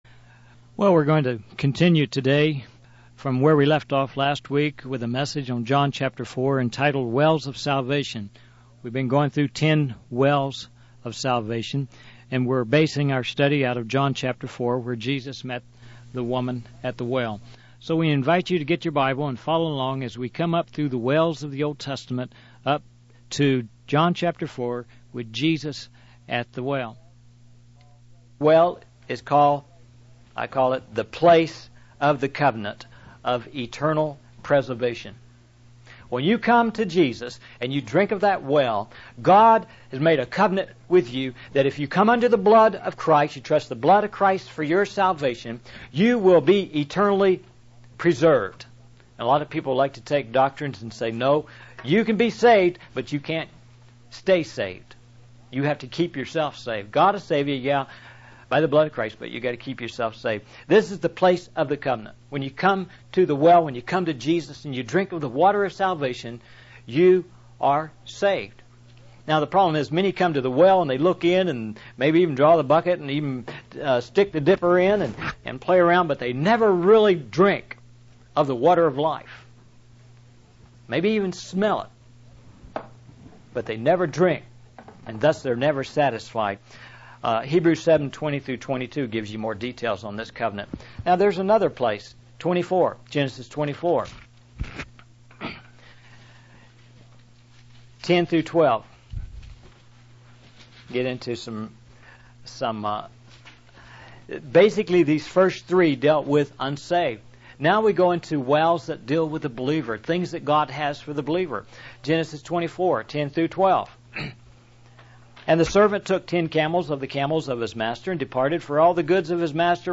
In this sermon, the preacher discusses the significance of wells in the Bible.